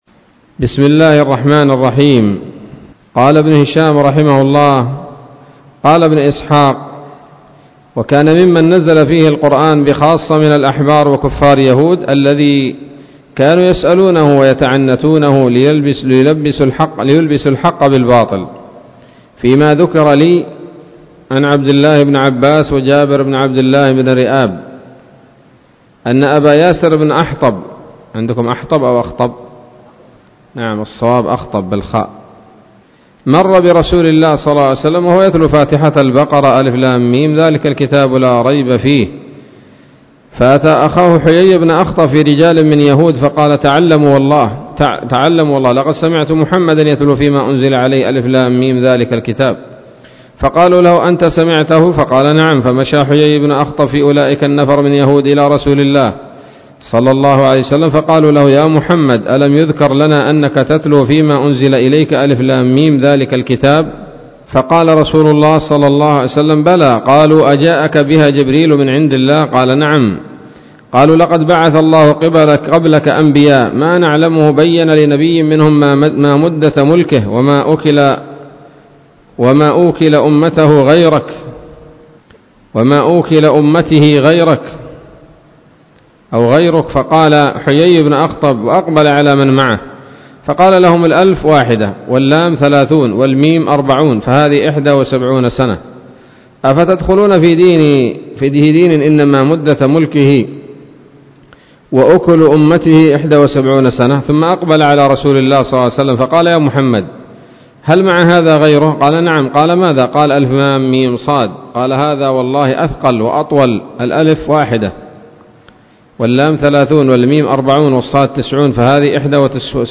الدرس الثاني والتسعون من التعليق على كتاب السيرة النبوية لابن هشام